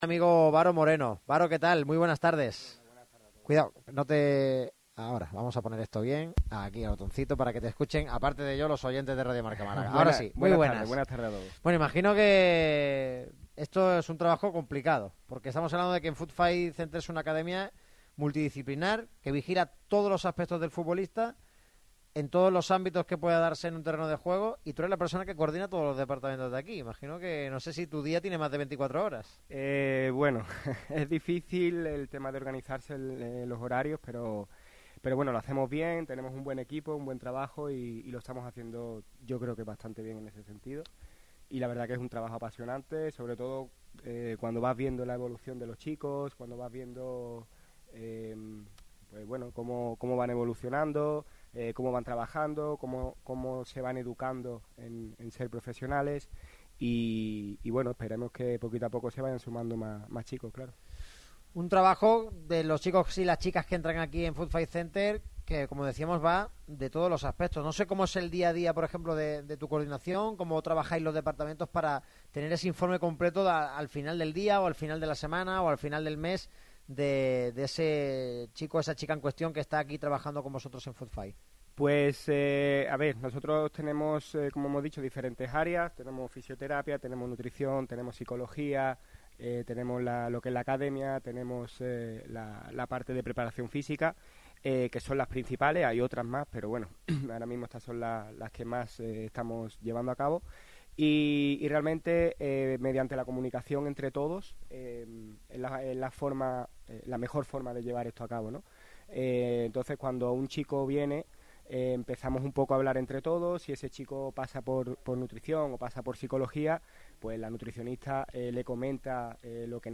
Radio MARCA Málaga traslada su equipo al FootFay Center